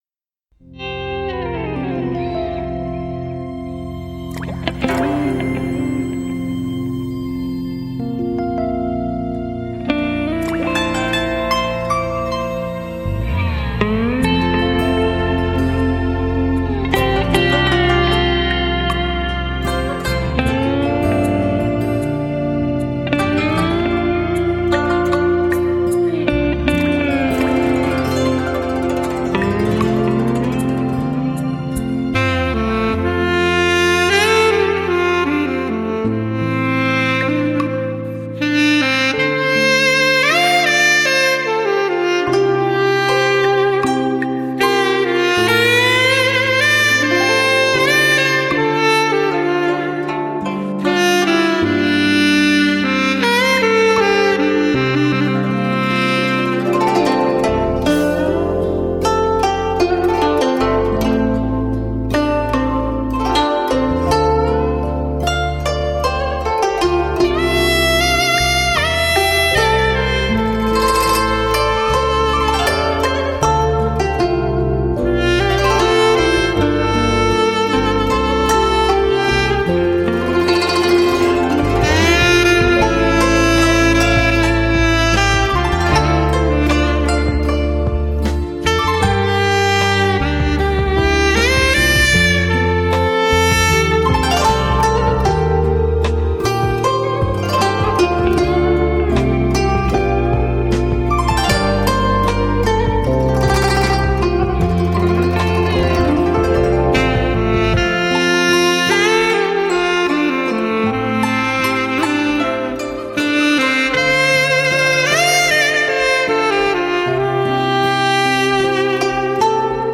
精选汽车专用无损音质
全方位多位环绕
发烧老情歌 纯音乐
极致发烧HI-FI人声测试碟